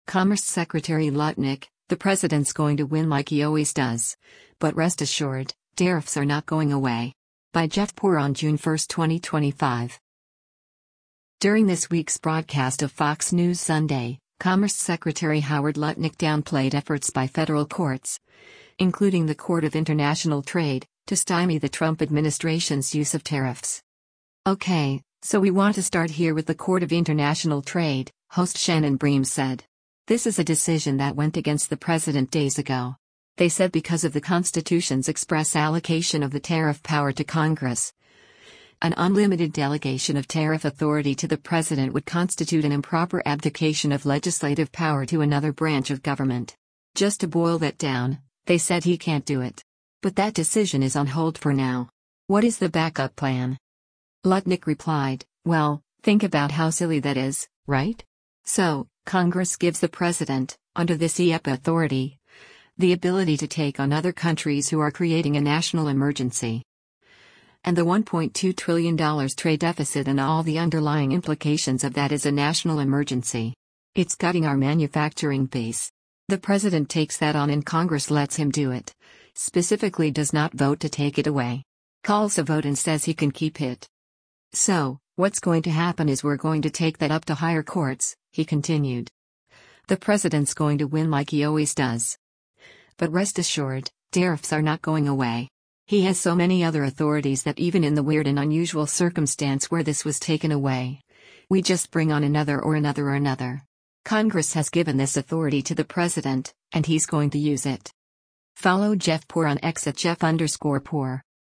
During this week’s broadcast of “Fox News Sunday,” Commerce Secretary Howard Lutnick downplayed efforts by federal courts, including the Court of International Trade, to stymy the Trump administration’s use of tariffs.